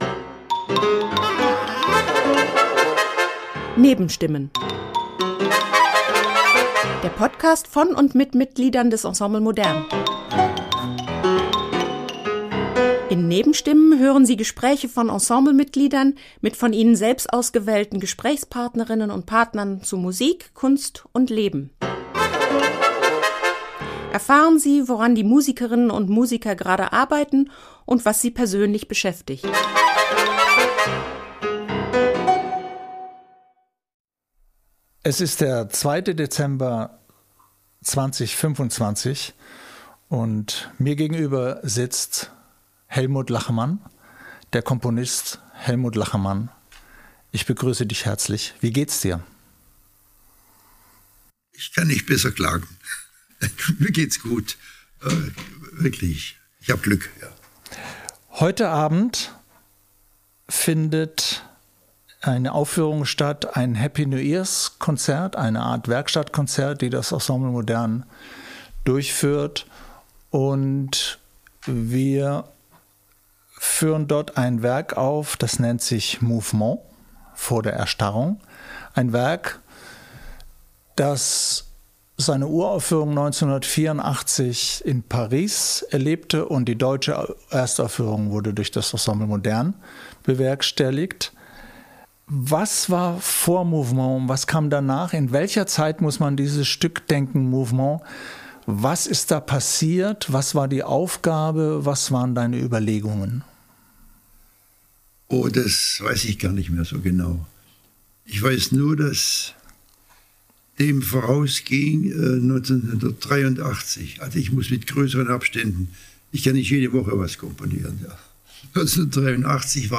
Beschreibung vor 3 Monaten In "Nebenstimmen" hören Sie Gespräche von Ensemble-Mitgliedern mit von ihnen selbst ausgewählten Gesprächspartnerinnen und -partnern zu Musik, Kunst und Leben.